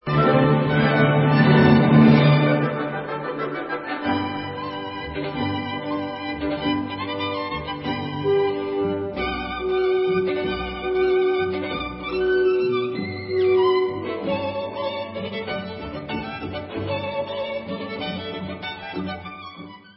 sledovat novinky v kategorii Vážná hudba